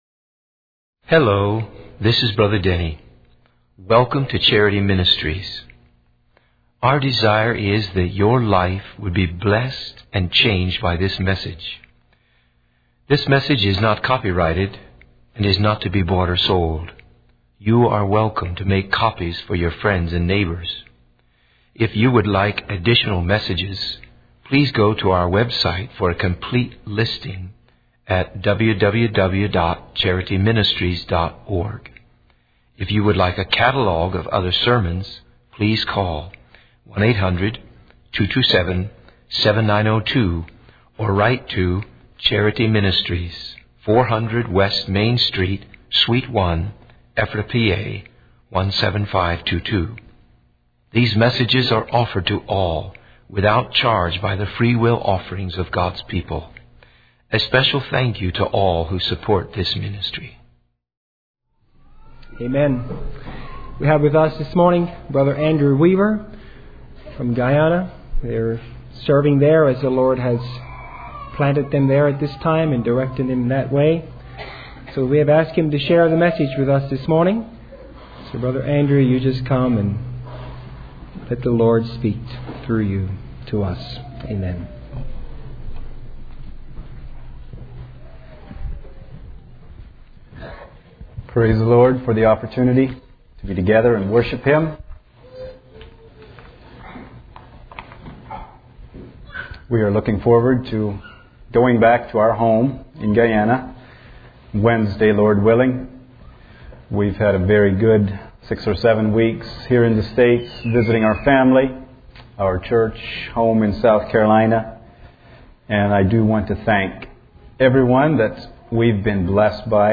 In this sermon, the speaker addresses the crowd before being executed and shares a message about following God's voice and making choices that bring peace to our hearts.